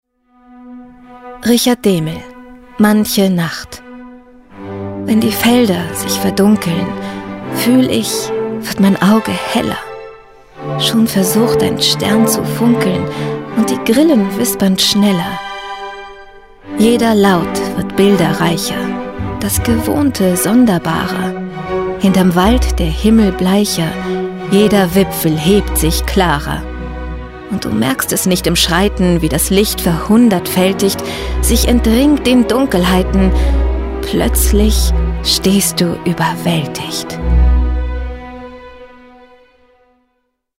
Synchronstimme von:
Marie-Bierstedt-Lyrik.mp3